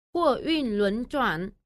Huòyùn lúnzhuǎn.
hua duyn luấn choản